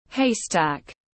Haystack /ˈheɪ.stæk/